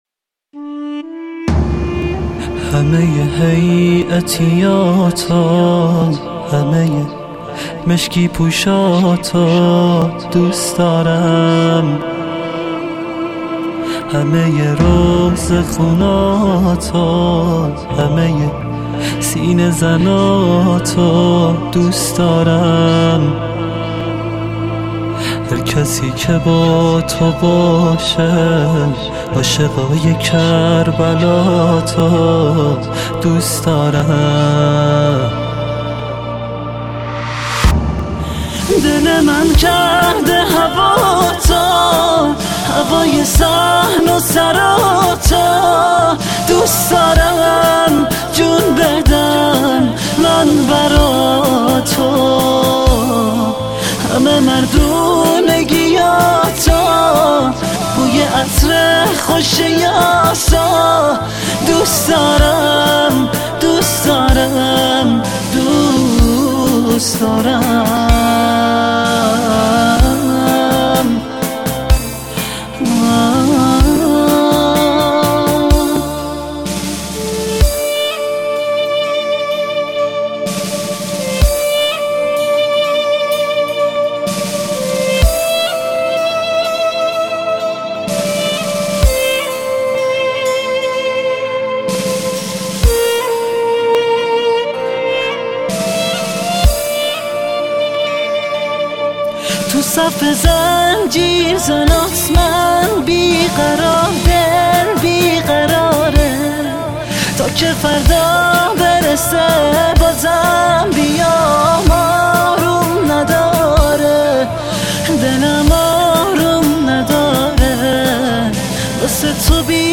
مذهبی و زیبای